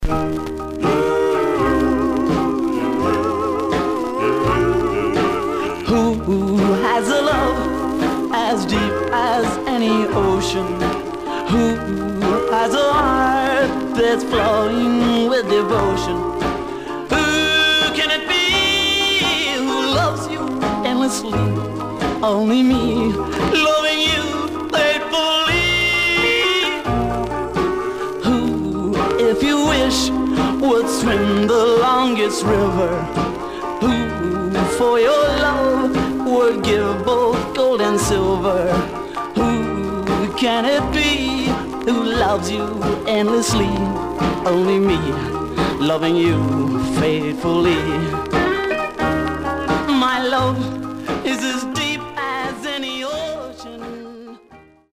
Some surface noise/wear Stereo/mono Mono
45s, Male Black Groups